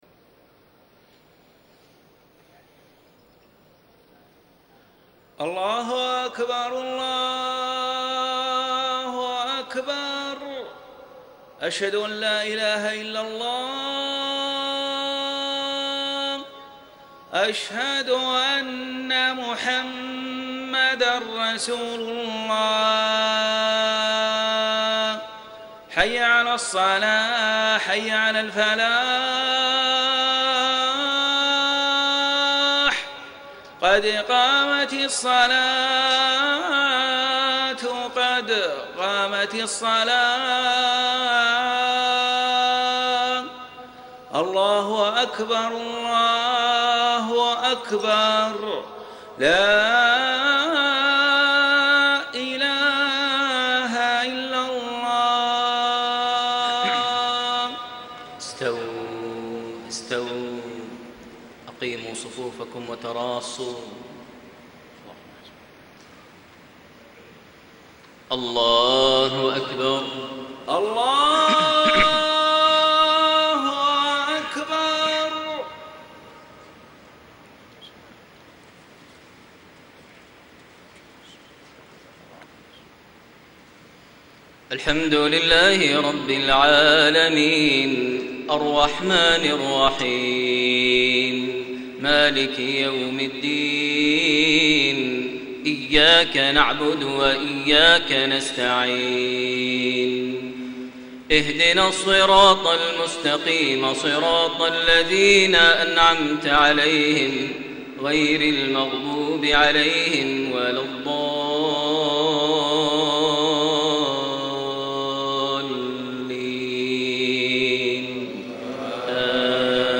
صلاة المغرب 7-8-1434 من سورة الأحزاب > 1434 🕋 > الفروض - تلاوات الحرمين